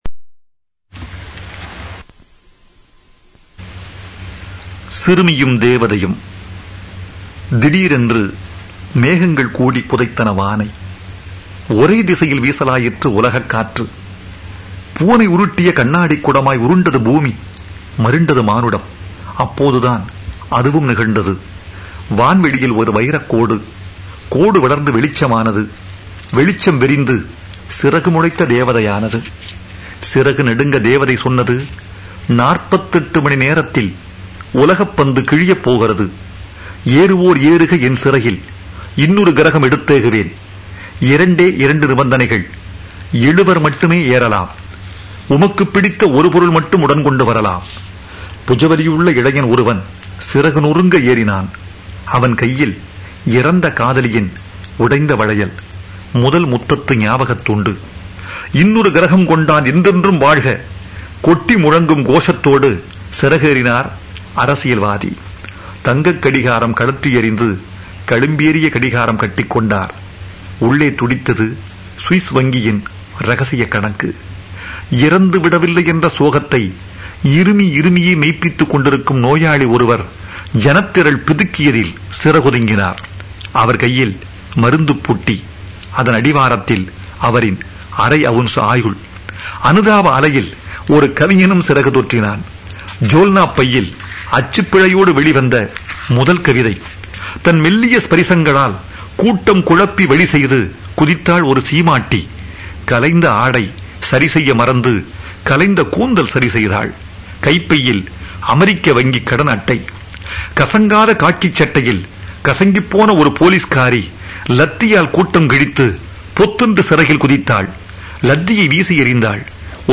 స్వరం – వైరముత్తు